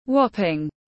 Lớn khác thường tiếng anh gọi là whopping, phiên âm tiếng anh đọc là /ˈwɒp.ər/ .
Whopping /ˈwɒp.ər/